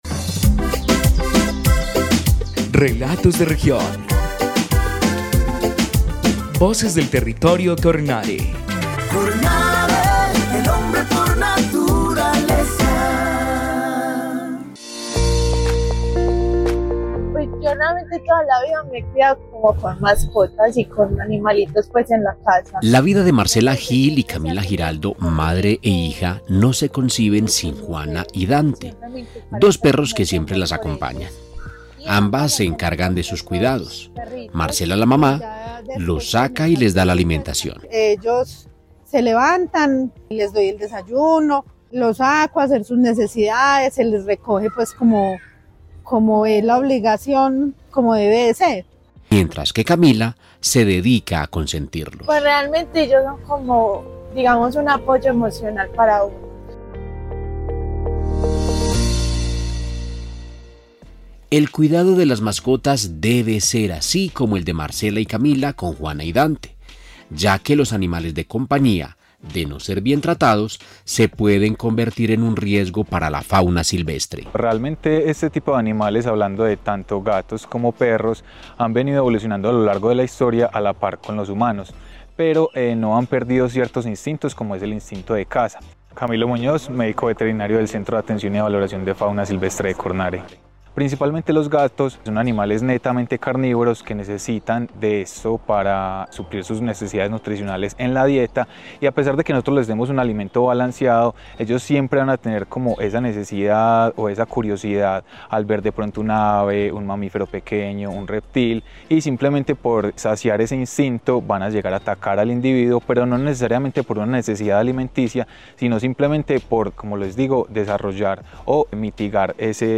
Programa de radio 2024